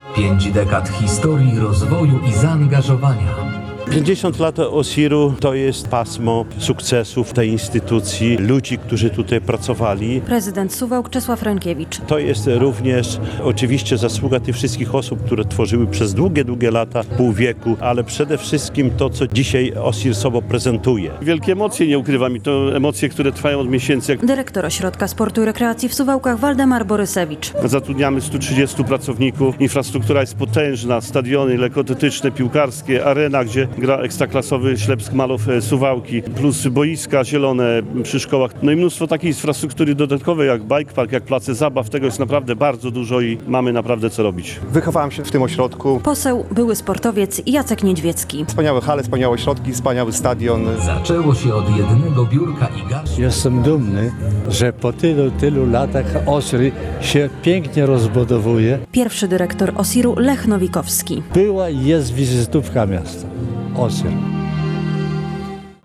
W hali "Suwałki Arena” odbyła się uroczysta gala, podczas której nie zabrakło wspomnień, podziękowań i wyjątkowych momentów.
Prezydent Suwałk Czesław Renkiewicz podkreślał, że na przestrzeni lat suwalski OSiR cały czas się rozwija.